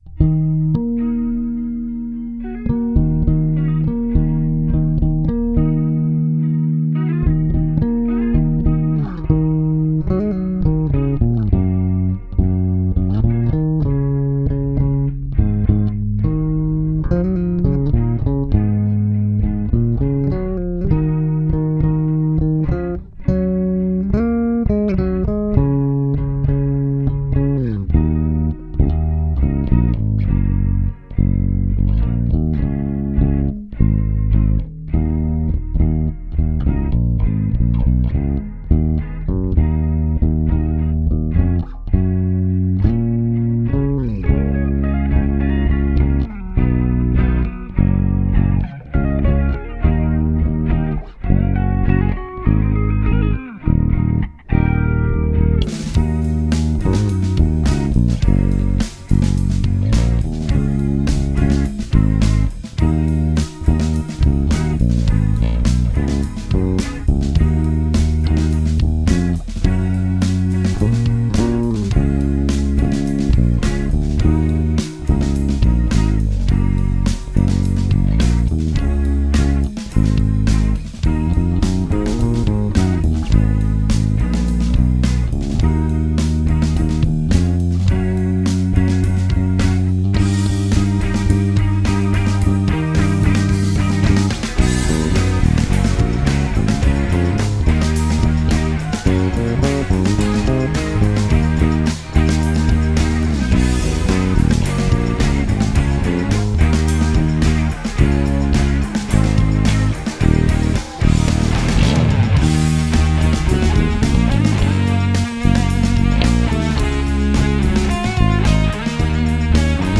instrumental
Cover / Bass only